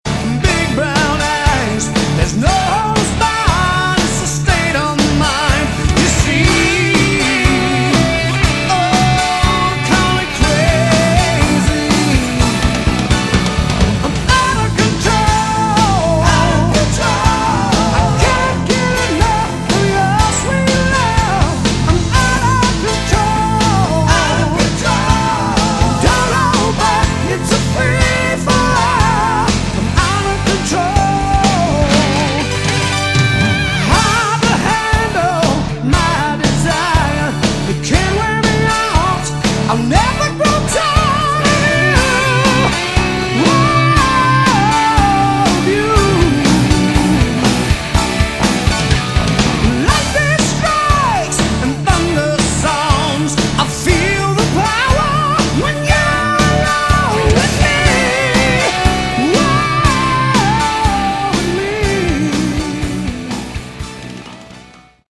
Category: Melodic Rock
vocals
keyboards, guitar
bass
drums